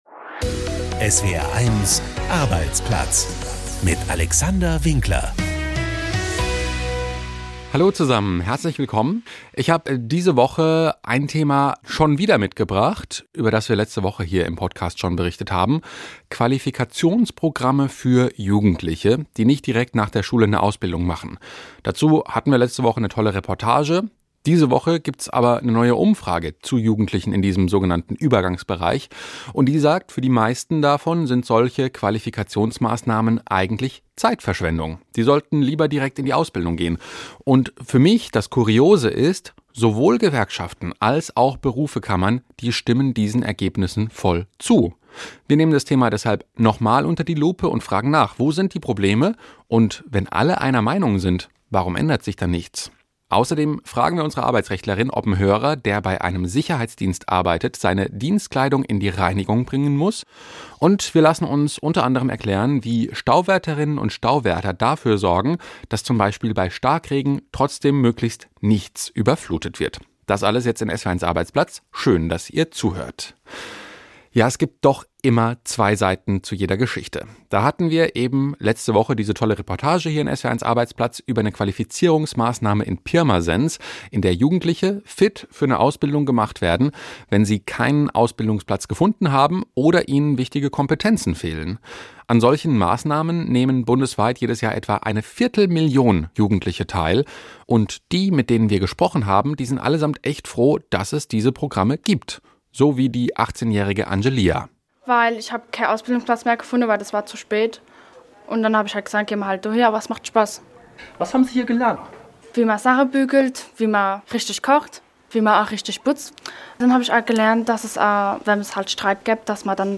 1 Krankenstand im Arbeitsleben – brauchen wir strengere Regeln? 17:04 Play Pause 9d ago 17:04 Play Pause Main Kemudian Main Kemudian Senarai Suka Disukai 17:04 Karenztag, Attestpflicht, Teilkrankschreibung – welche Hebel gibt es? ++ Statistische Effekte und Erfahrungen im Ausland – Interview mit OECD-Experte ++ Überflüssig oder wichtig?